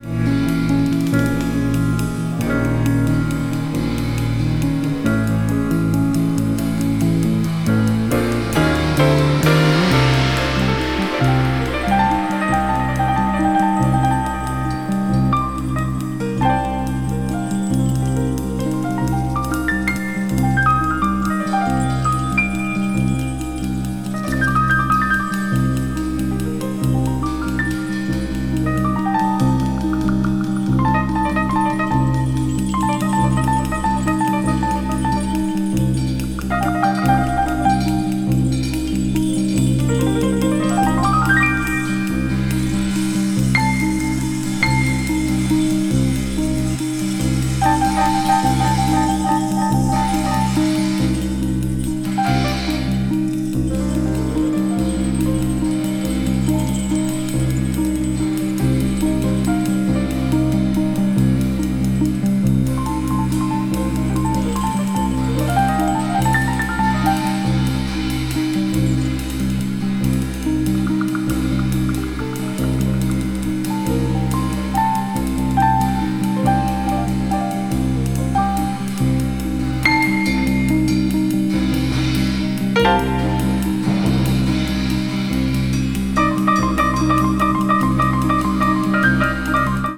contemporary jazz   crossover   fusion   spiritual jazz